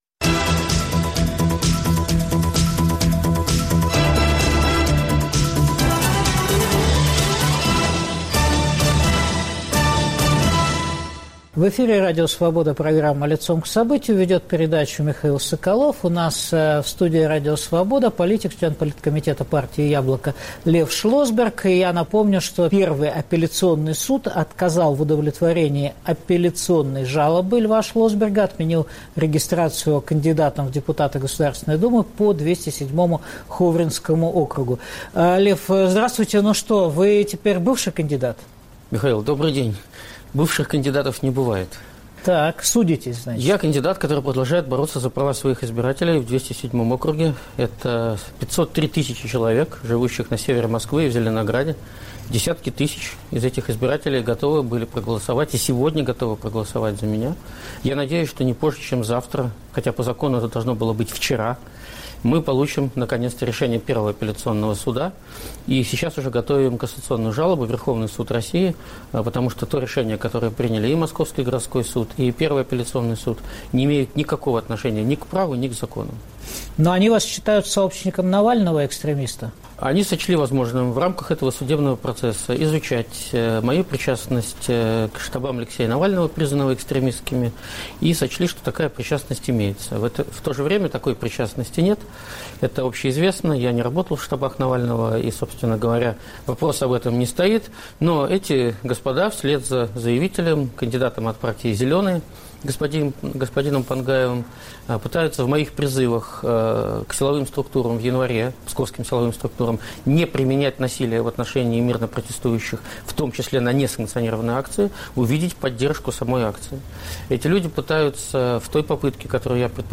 Какова альтернатива курсу "партии власти" на подачки и репрессии? В эфире член Политкомитета партии «Яблоко» Лев Шлосберг.